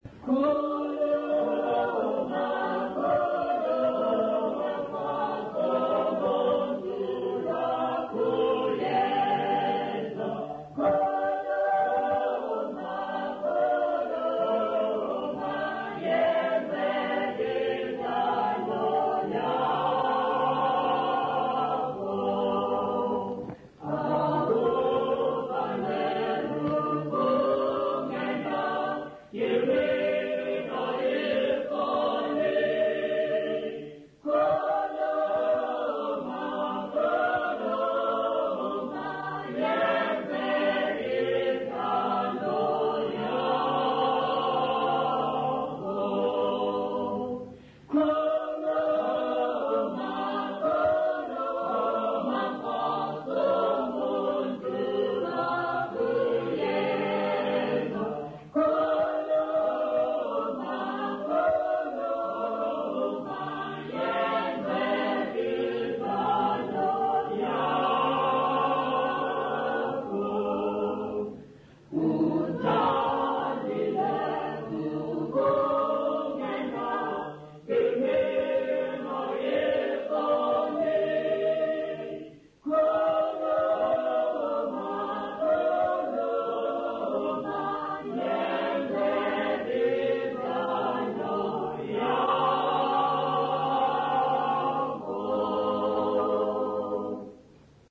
Intshanga church music workshop participants
Folk music South Africa
Hymns, Zulu South Africa
field recordings
Unaccompanied church hymn.